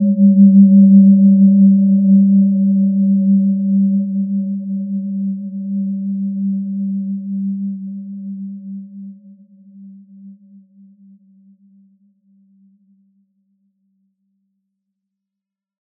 Gentle-Metallic-3-G3-mf.wav